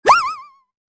Cartoon Slipping Sound Effect
Comical cartoon slipping sound effect with a playful slide and exaggerated fall.
Funny sounds.
Cartoon-slipping-sound-effect.mp3